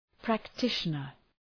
Προφορά
{præk’tıʃənər}